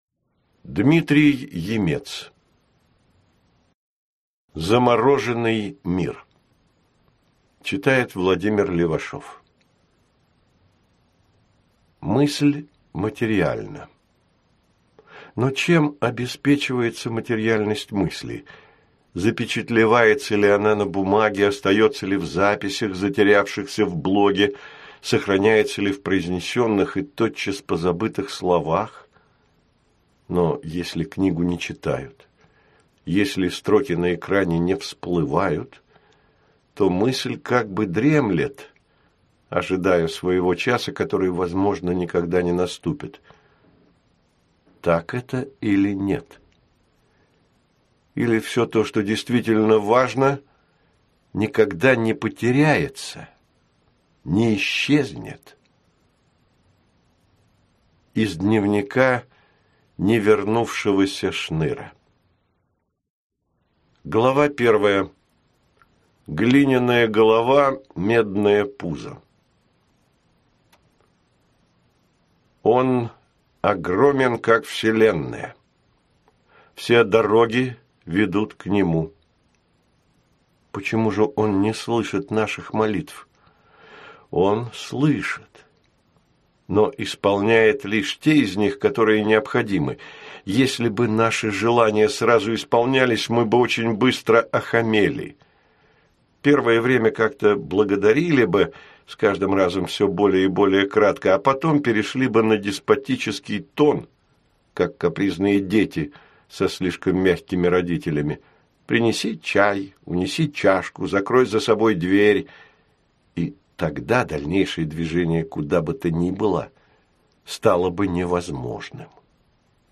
Аудиокнига Замороженный мир | Библиотека аудиокниг